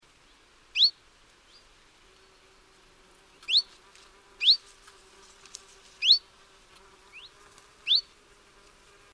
Chiffchaff calls
All from Northwestern Estonia, 10-11 September 2005.
Different individual.